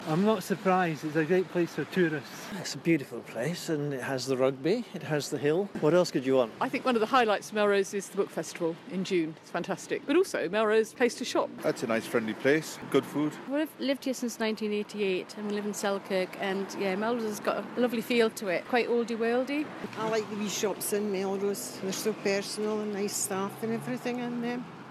LISTEN: We asked people in Melrose what makes the town special after it was crowned the Best Place to Live in Scotland by the Sunday Times.